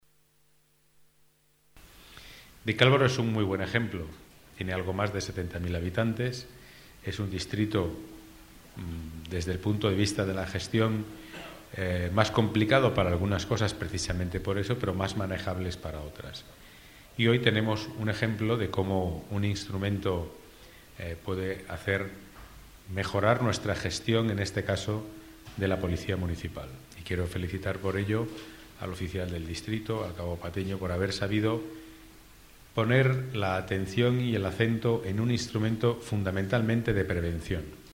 Nueva ventana:Declaraciones del delegado de Seguridad, Pedro Calvo: Comercio Seguro en Vicálvaro